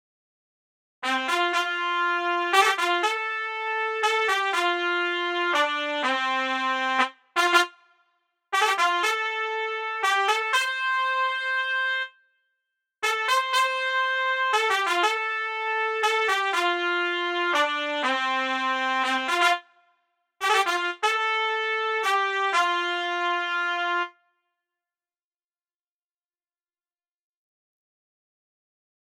誰だ！このド下手クソなトランペットを吹いてるのは！